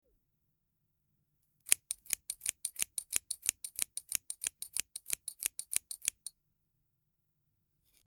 Scissors Cutting Hair 04
Scissors_cutting_hair_04.mp3